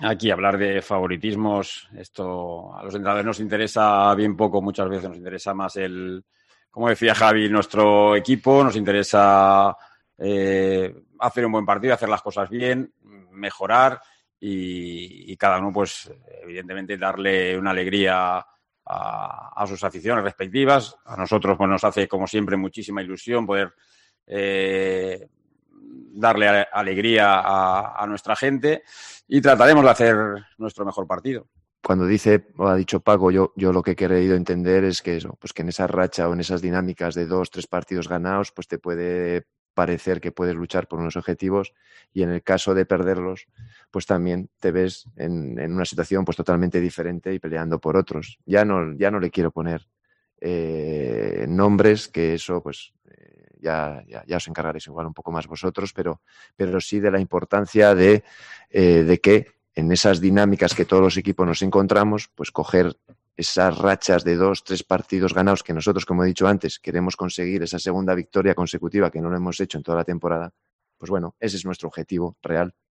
Los entrenadores del Levante UD y del Valencia CF comparecen en la previa del derbi de este viernes en el Ciutat de València
AUDIO. López y Gracia en la previa del derbi